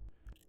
Slurp noise.wav